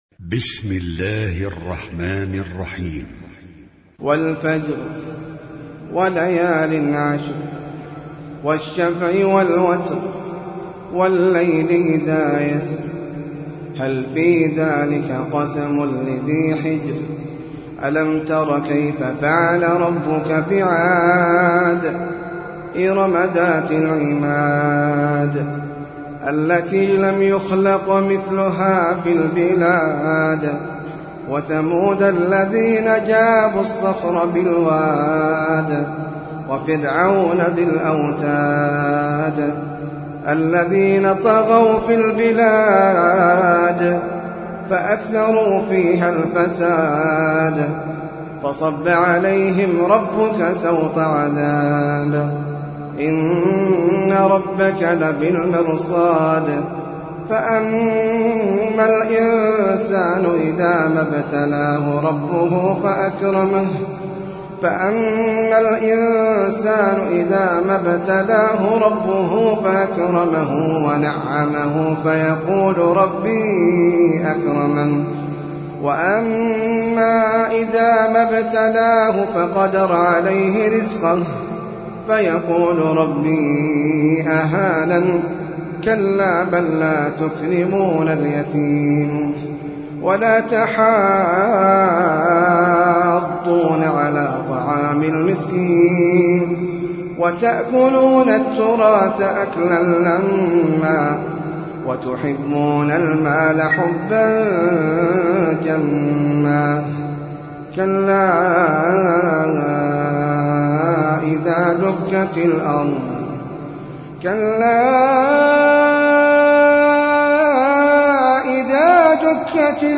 سورة الفجر - المصحف المرتل (برواية حفص عن عاصم)
جودة عالية